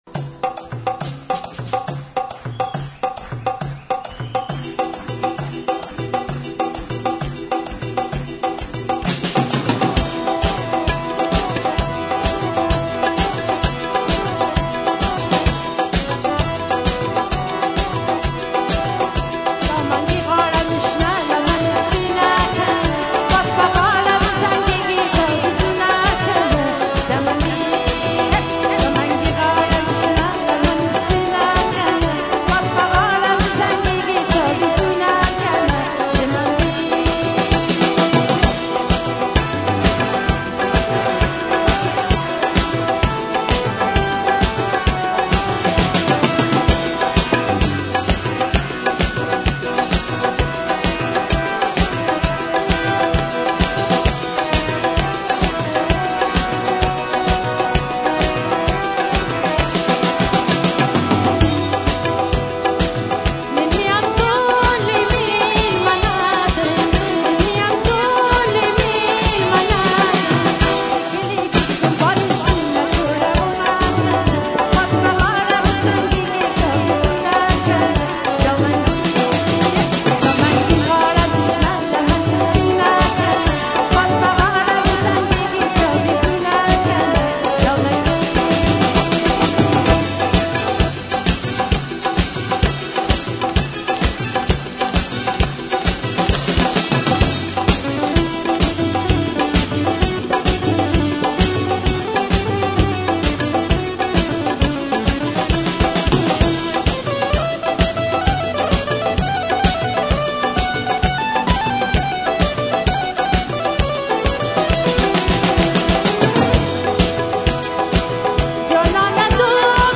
Concert (germany)